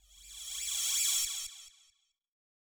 K-4 Flange Cymbal Reverse.wav